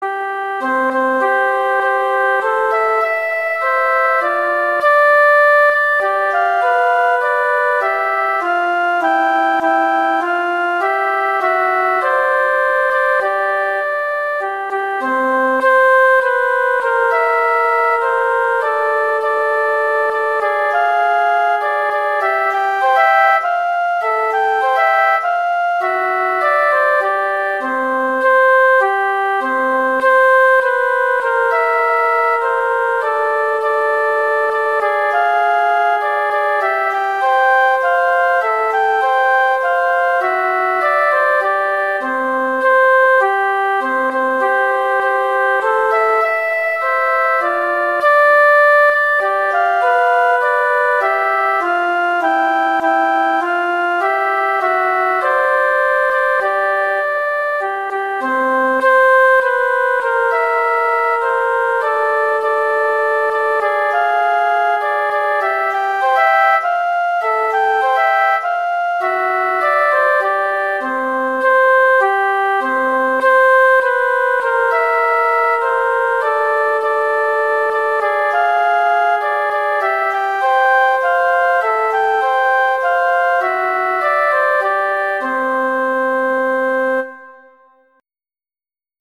arrangements for two flutes
traditional, irish, children